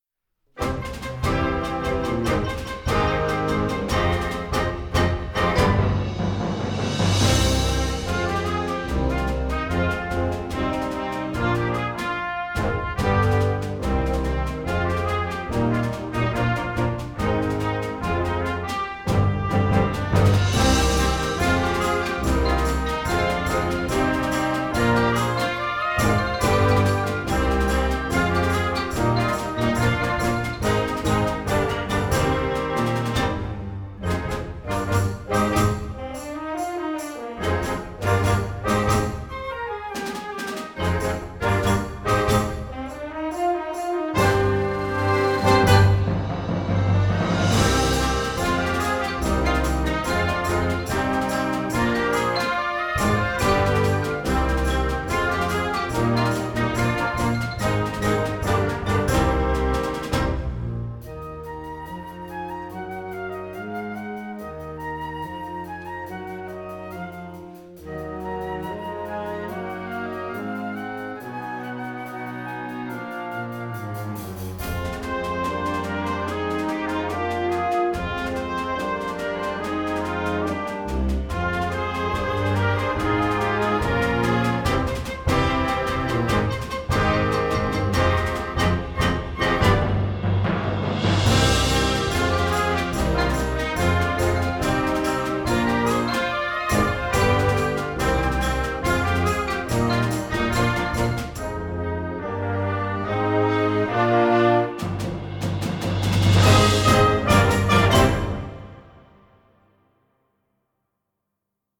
Gattung: Ouvertüre für Jugendblasorchester
Besetzung: Blasorchester